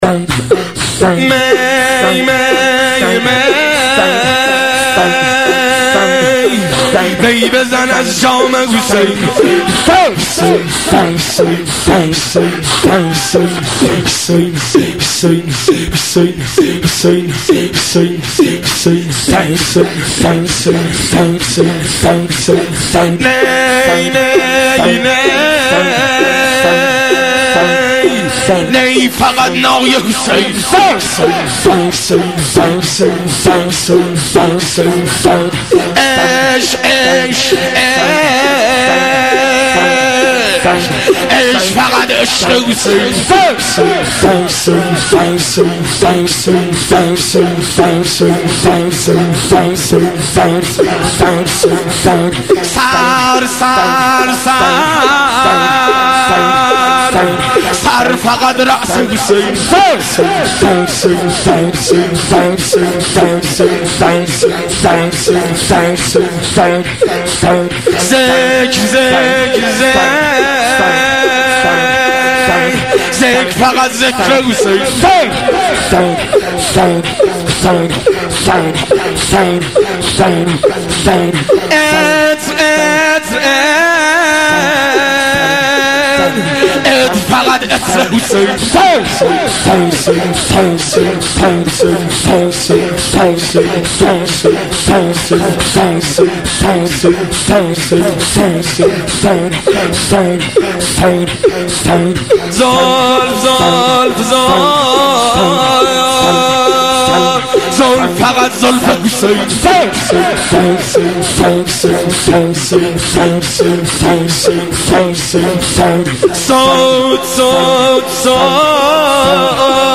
شور و شعرخوانی گلچین مداحی 1381
شور و شعرخوانی گلچین مراسمات 1381